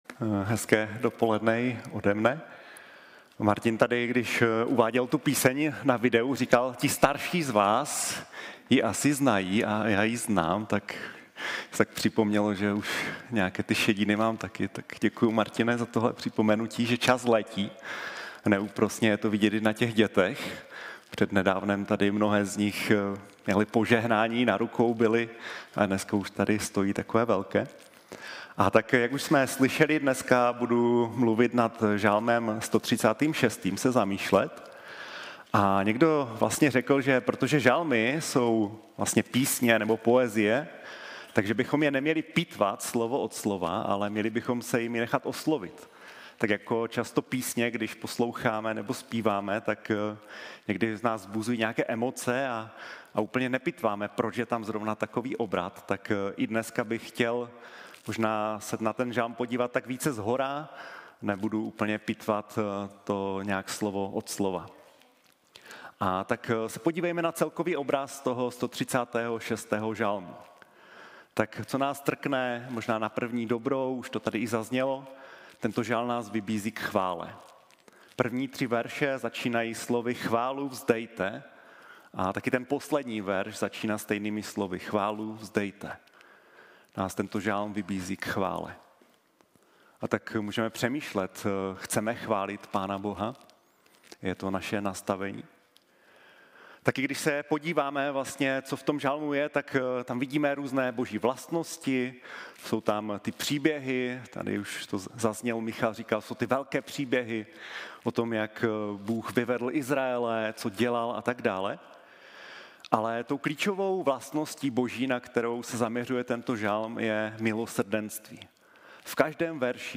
1 Kázání